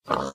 assets / minecraft / sounds / mob / pig / say1.ogg